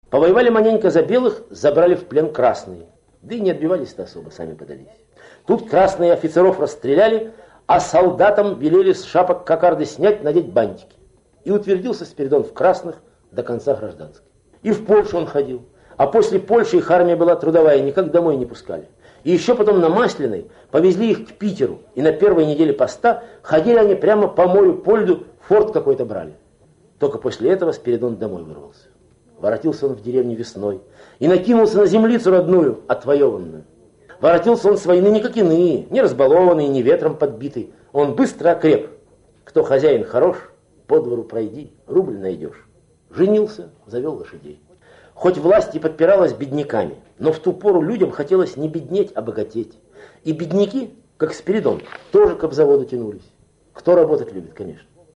Solzhenitsyn Reading 'Gulag Archipelago'